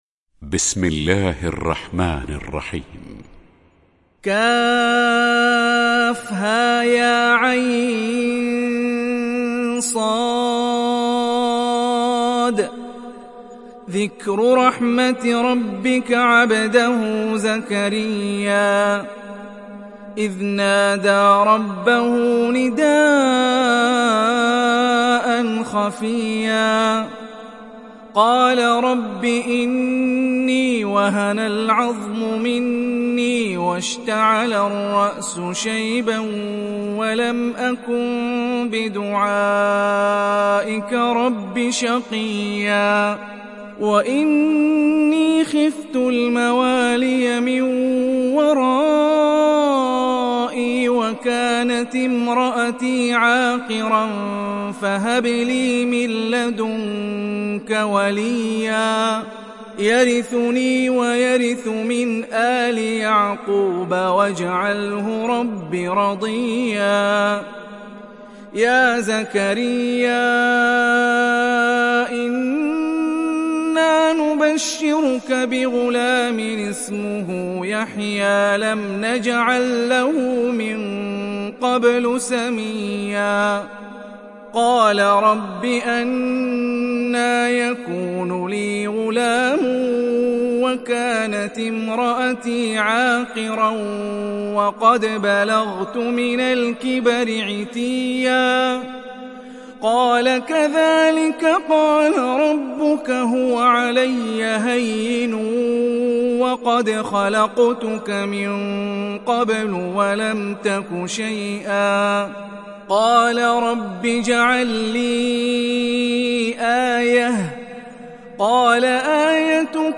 Meryem Suresi mp3 İndir Hani Rifai (Riwayat Hafs)